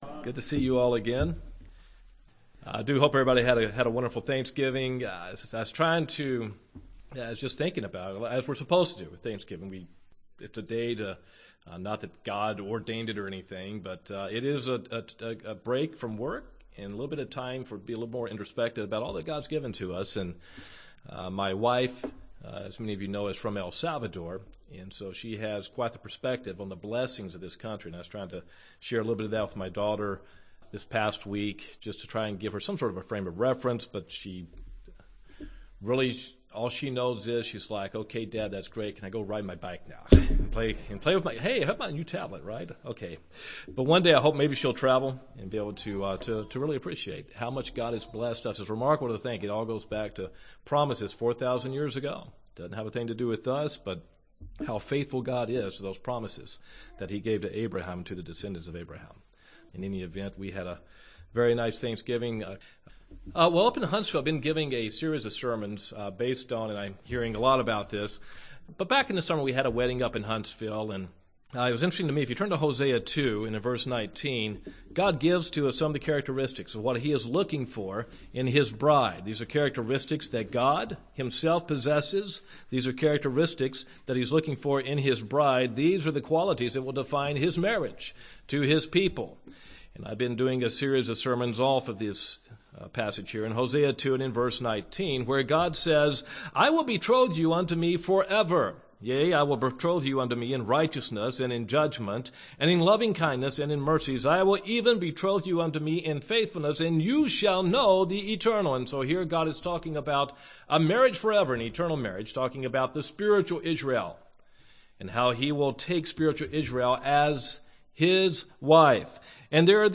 Sermons
Given in Gadsden, AL Huntsville, AL